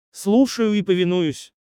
Звук с фразой Джинна Слушаю и повинуюсь